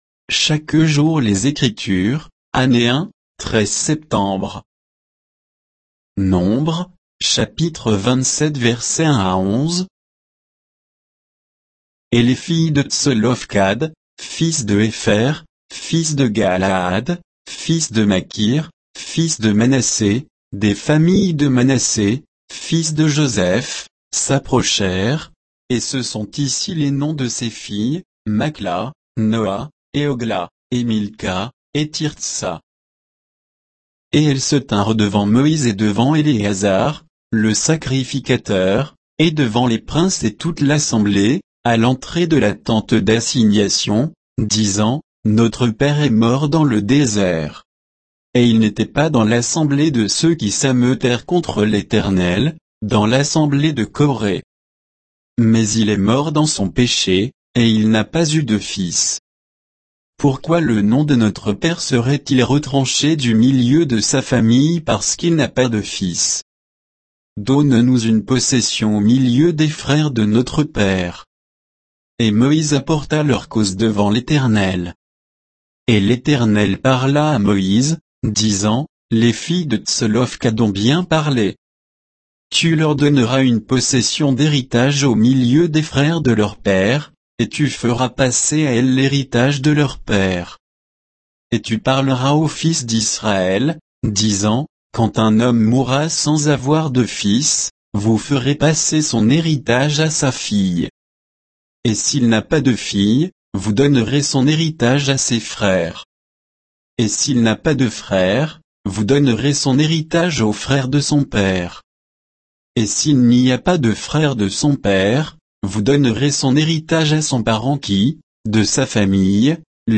Méditation quoditienne de Chaque jour les Écritures sur Nombres 27